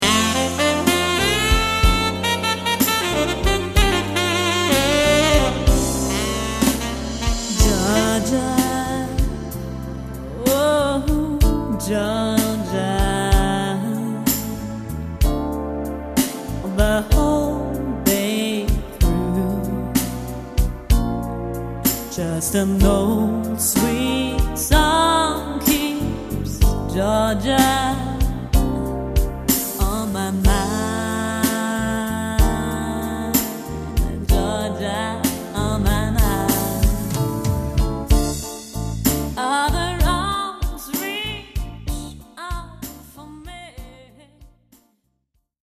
Die Gala- und Partyband aus NRW für Ihre Hochzeit.